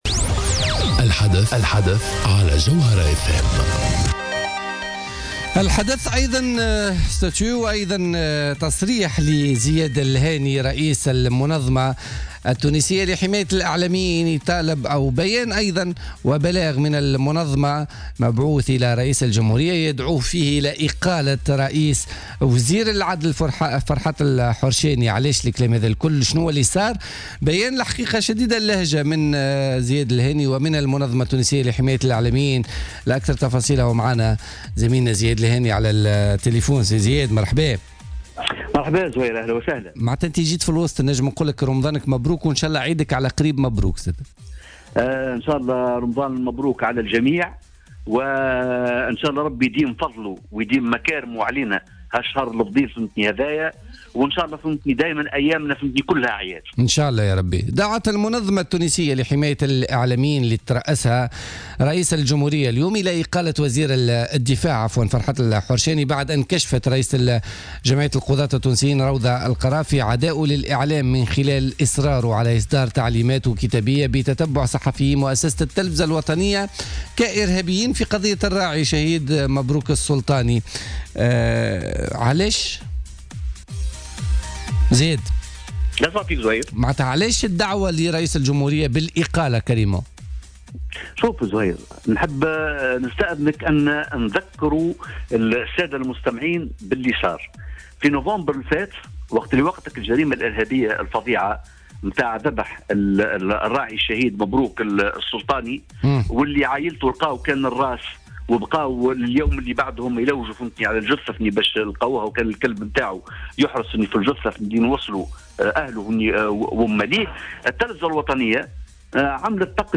حوار مع الجوهرة أف أم